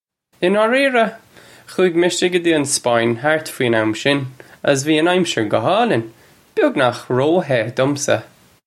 Pronunciation for how to say
In aw-ree-ra? Khoo-ig misha guh jee un Spaw-in hart fween am shin uggus vee un amsher guh hawleen, byugnakh roe-heh dumsa.